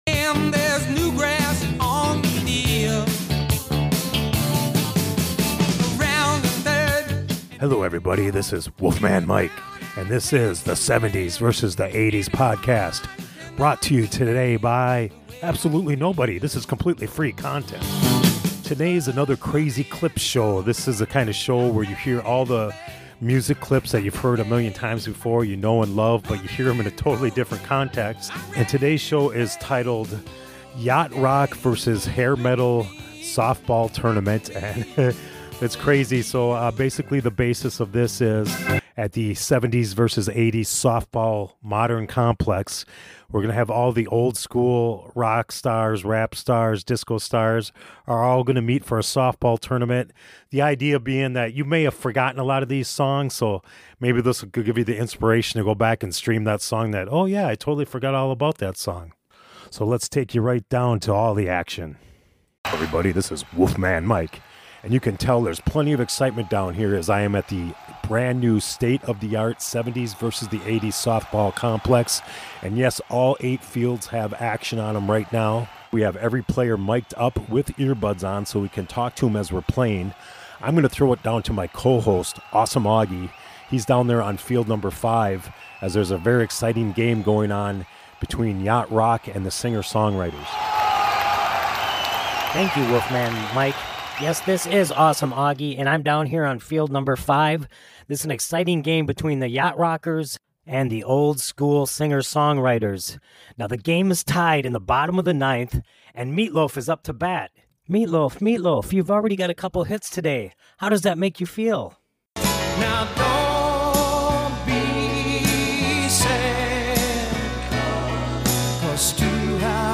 Crazy Clips Show Part 2: Meat Loaf, Ozzy Osbourne, Skee Lo, and many others “compete" and sound off "in their own voices" in a Softball Tournament for the ages.